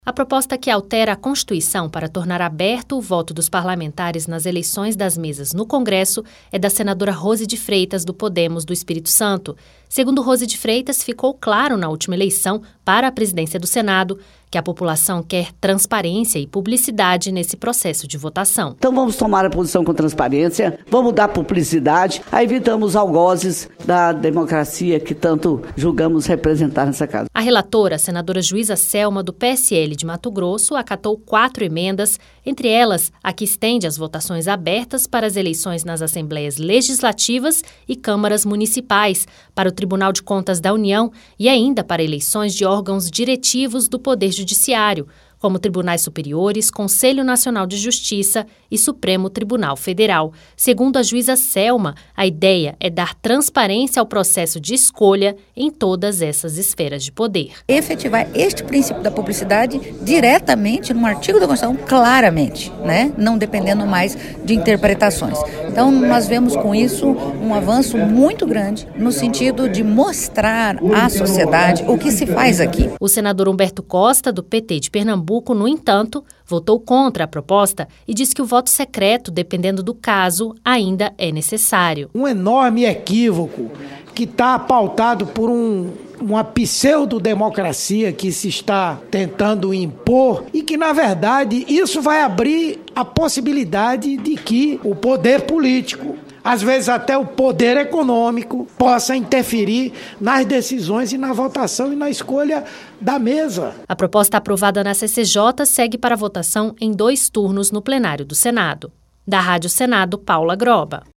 O relatório aprovado inclui voto aberto ainda em Assembleias Legislativas e em Tribunais Superiores de Justiça. Ouça mais detalhes na reportagem